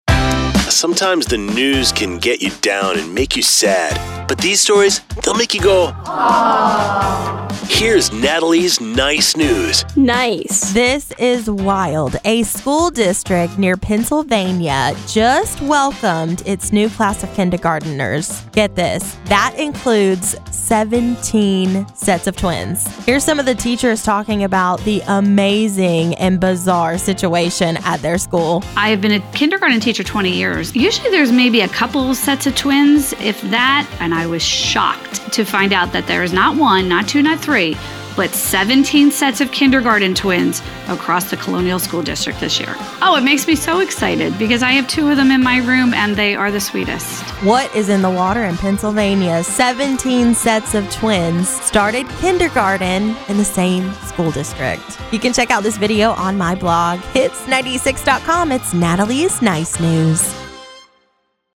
Chattanooga, Tennessee—(Wise Brother)